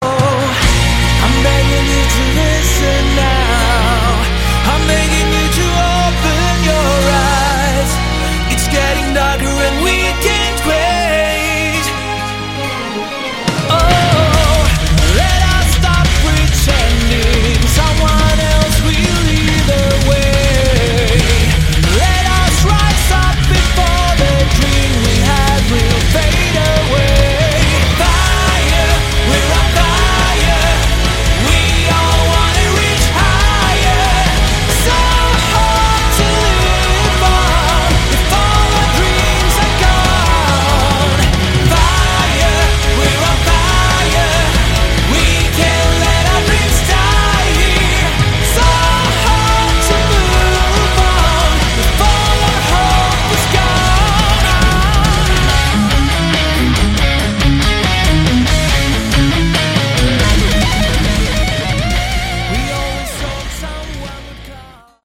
Category: Melodic Metal
drums